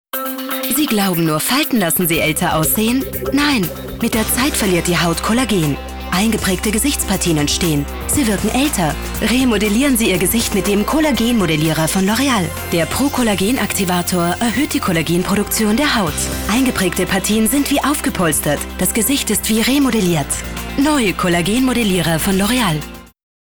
STIMMLAGE: kräftig, voll, warm, markant, sinnlich, zwischen 25-45 J. einsetzbar.
Sprechprobe: Industrie (Muttersprache):
female voice over talent german.